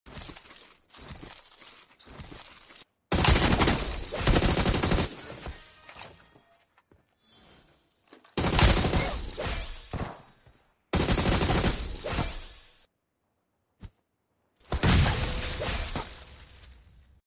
وش رأيكم بأصوات تأثير الهيدات sound effects free download
وش رأيكم بأصوات تأثير الهيدات والبدي والنيد ل AUG الجديد 😍